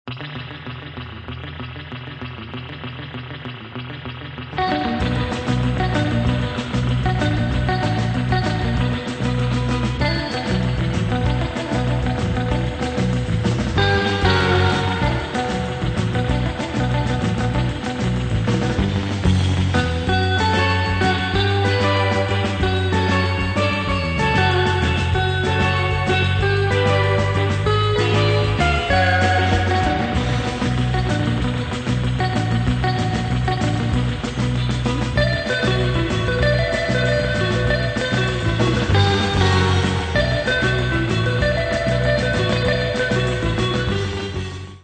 nervous medium instr.